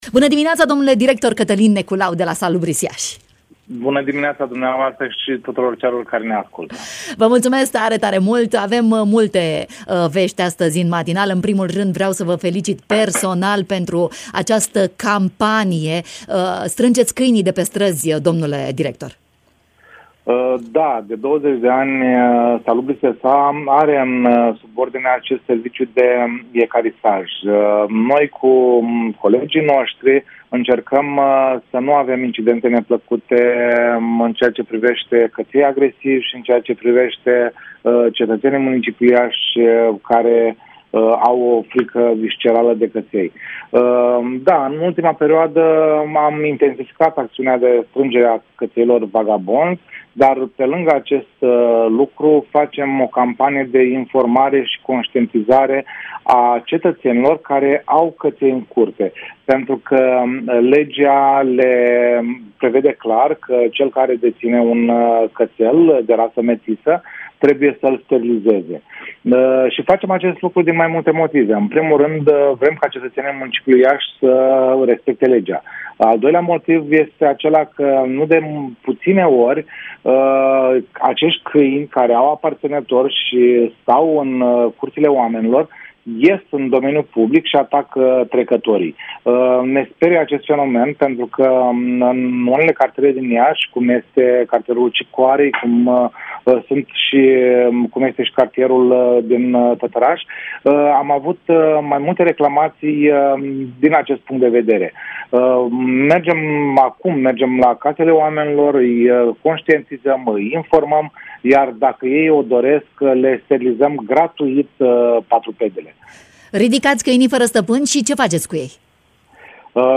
invitat în matinalul Radio România Iași